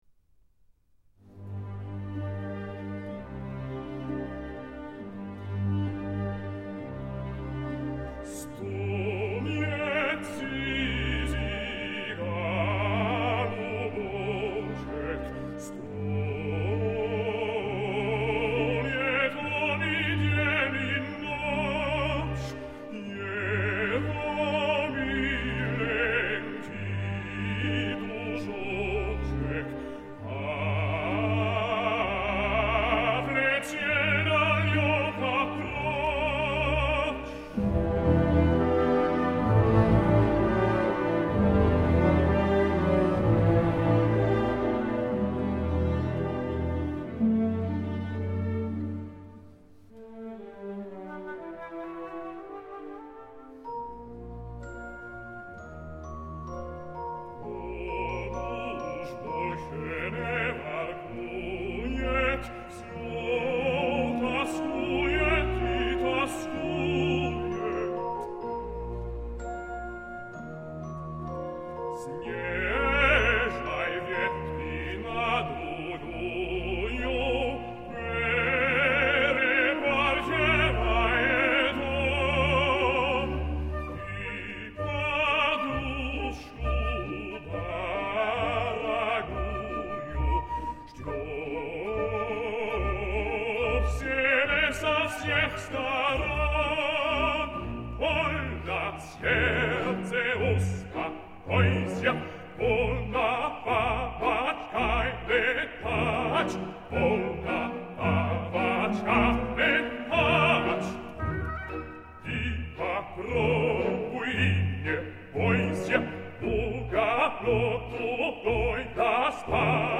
ballet Prokofiev Stravinsky Symphonie Tchaïkovski